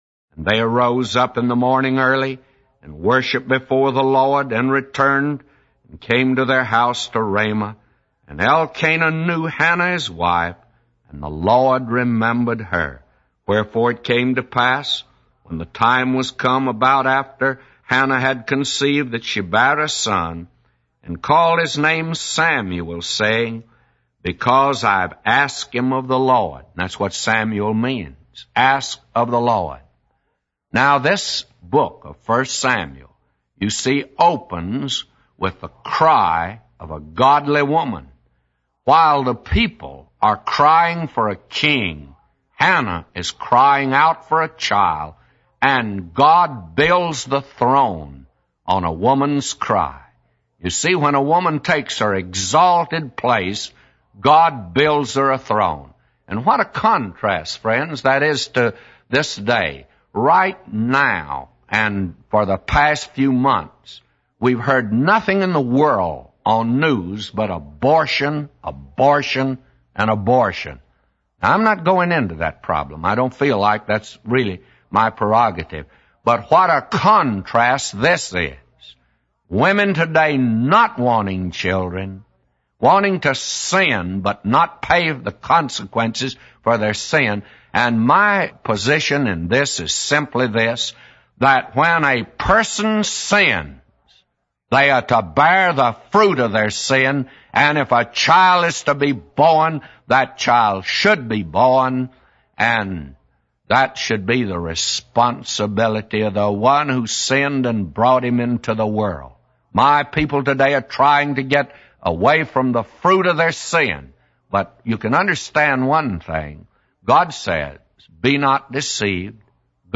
A Commentary By J Vernon MCgee For 1 Samuel 1:19-999